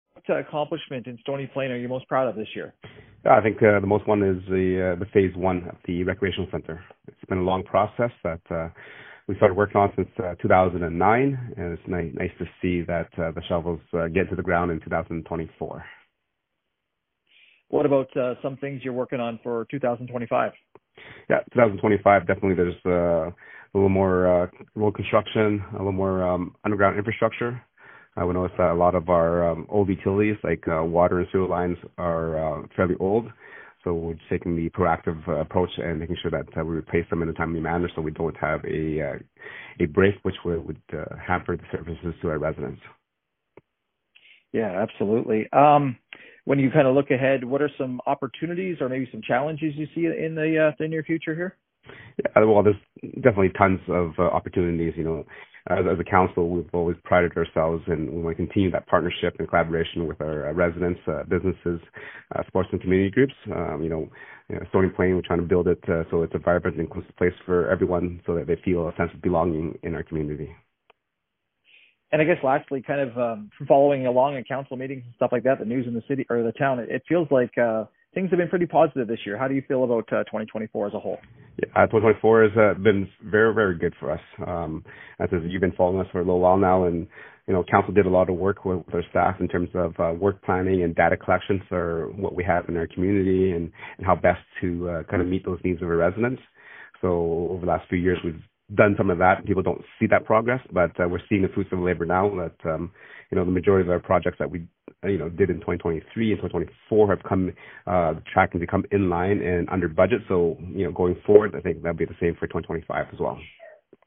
AB Year end interview with Stony Plain mayor, William Choy.